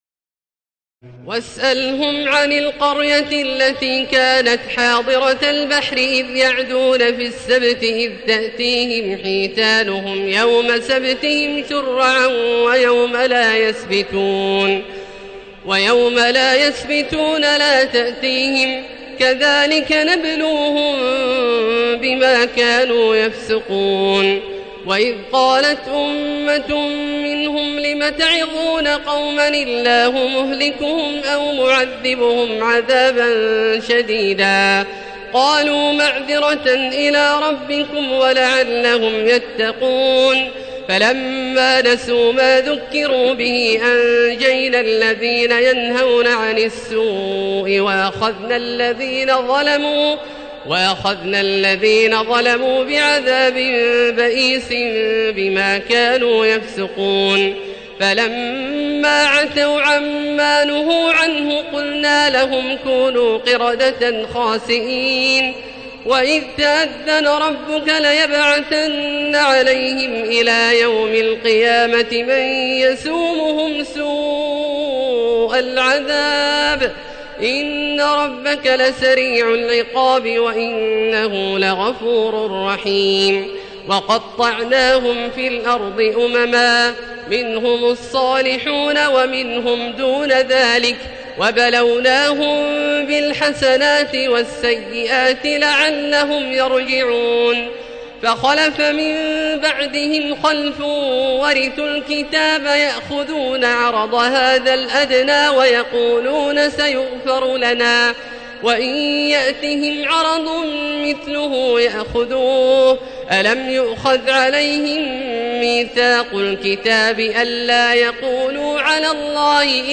تراويح الليلة الثامنة رمضان 1437هـ من سورتي الأعراف (163-206) والأنفال (1-40) Taraweeh 8 st night Ramadan 1437H from Surah Al-A’raf and Al-Anfal > تراويح الحرم المكي عام 1437 🕋 > التراويح - تلاوات الحرمين